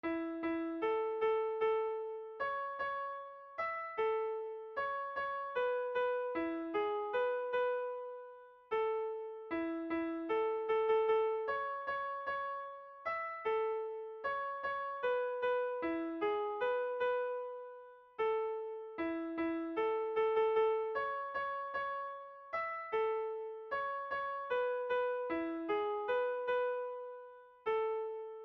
Note DM : 76 à la noire (et non à la croche) semble un tempo plus vraisemblable.